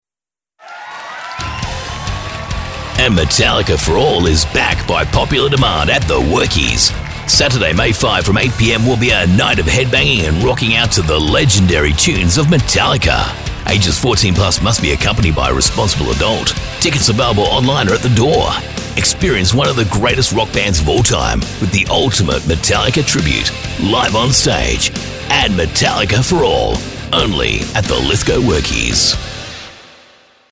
Dynamic, approachable, friendly and natural Australian VoiceOver
Radio / TV Imaging
Rock Read, Manly,Bold